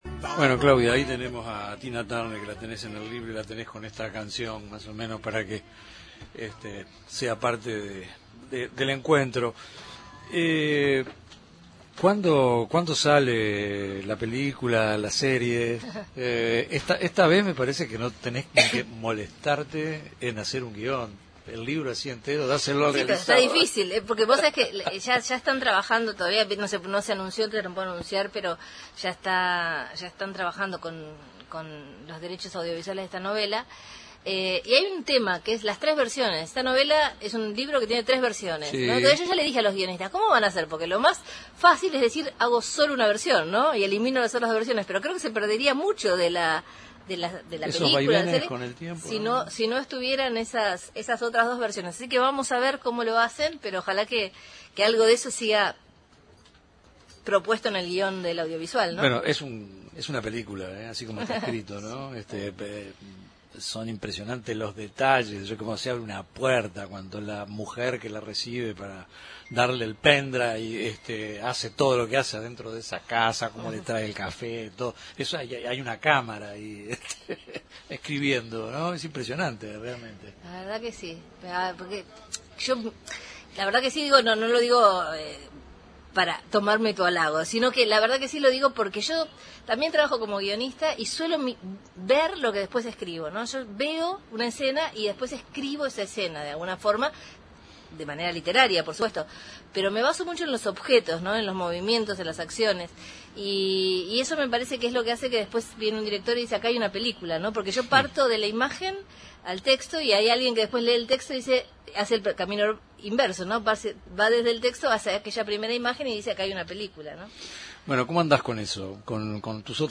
En el estudio móvil de los Medios Públicos recibimos a Claudia Piñeiro, una de las voces más potentes de la literatura argentina contemporánea, para conversar sobre su ensayo La muerte ajena. Un libro imprescindible que reflexiona, con sensibilidad y lucidez, sobre cómo la sociedad gestiona la muerte y el duelo, y qué lugar ocupa lo colectivo en ese tránsito profundamente humano.
Una charla íntima y necesaria con una autora que siempre incomoda desde el pensamiento crítico.